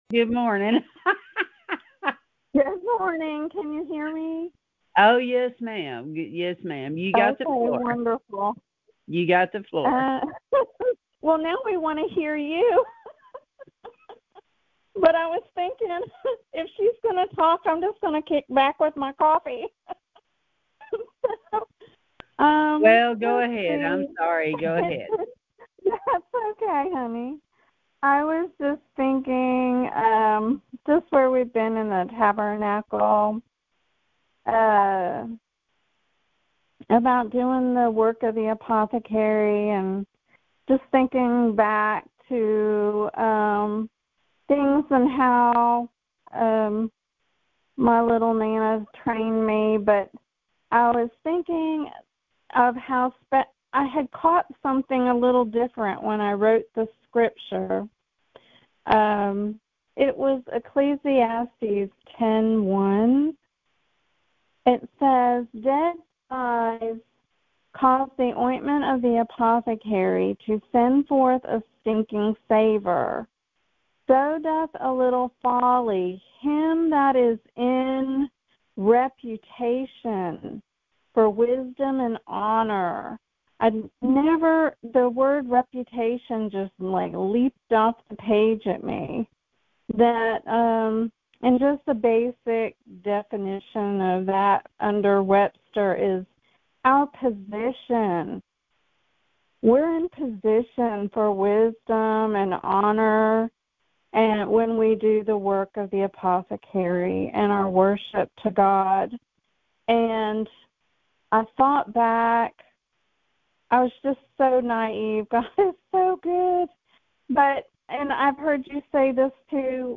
Sermons | Garden of Eden Ministries